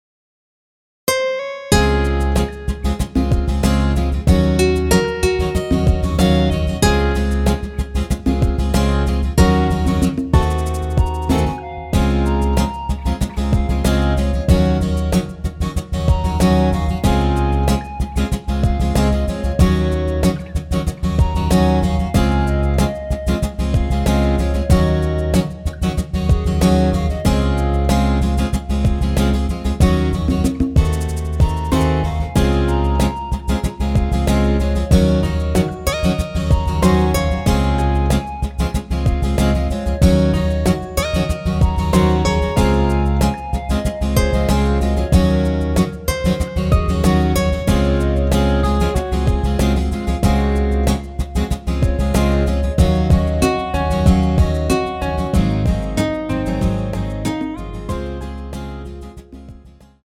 원키에서(+2)올린 멜로디 포함된 MR입니다.(미리듣기 확인)
C#
앞부분30초, 뒷부분30초씩 편집해서 올려 드리고 있습니다.
중간에 음이 끈어지고 다시 나오는 이유는